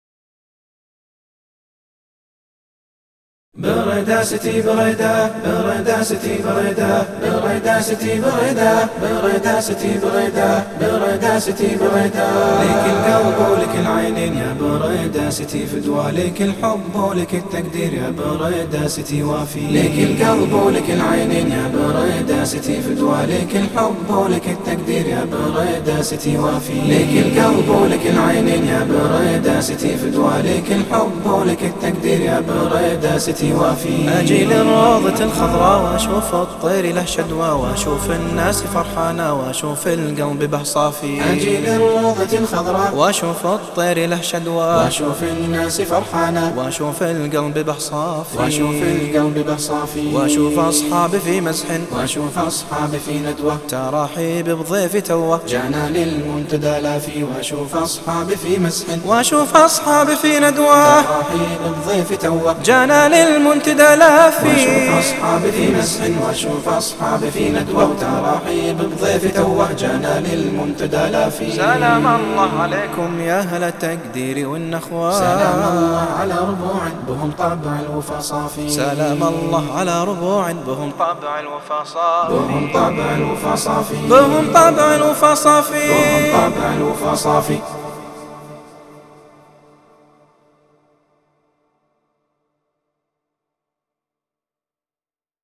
ألحان وأداء المنشد
خآآآمة صوت رآآئعه ..